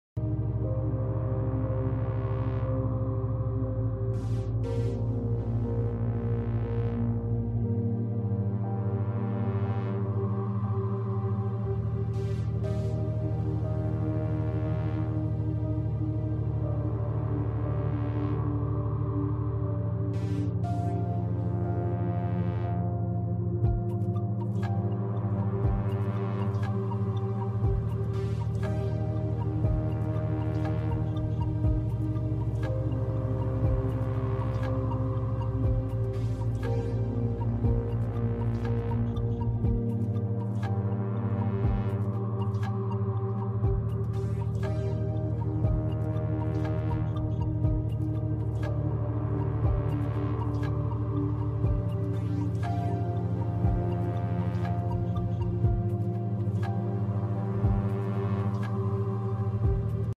Check out these binaural beats designed to improve your focus and concentration in 5 minutes. Perfect for studying, working, or any cognitive tasks. This track features 13Hz Beta Waves, which enhances alertness and problem-solving skills.